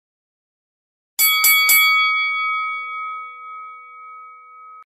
Boxing Bell Sound Effect Free Download
Boxing Bell